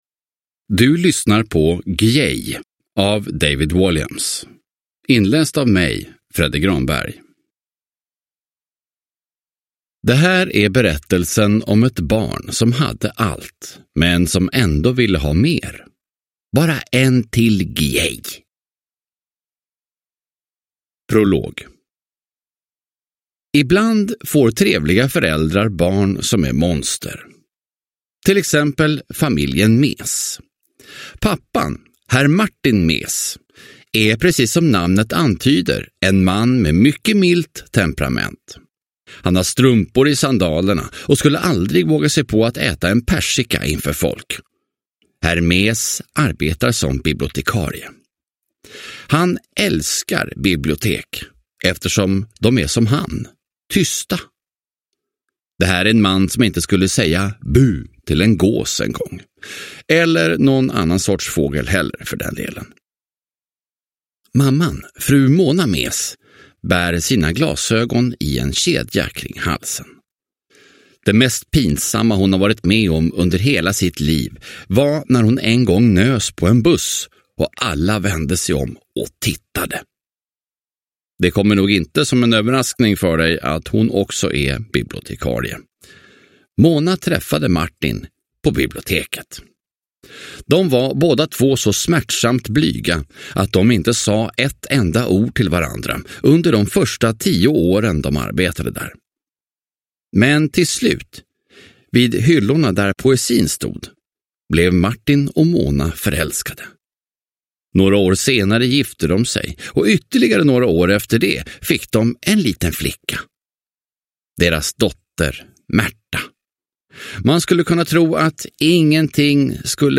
Gjej – Ljudbok – Laddas ner